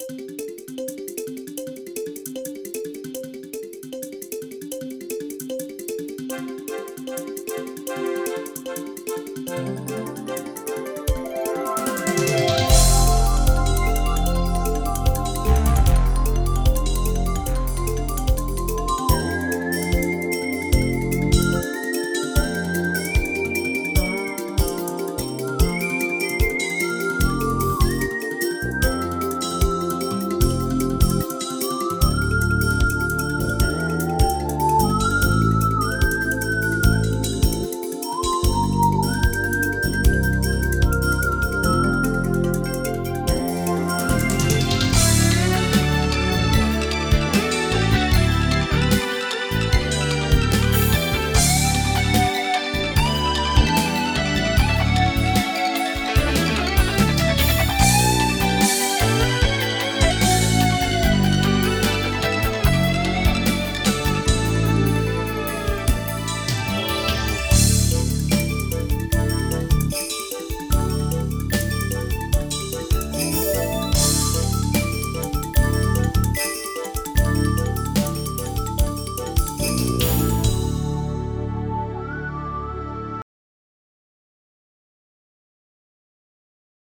• On-Board Demos